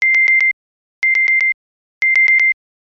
【効果音】切れかけた蛍光灯
蛍光灯が「パカパカ」と切れかけた音です。